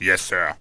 spearman_ack6.wav